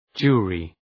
Shkrimi fonetik {‘dʒu:rı}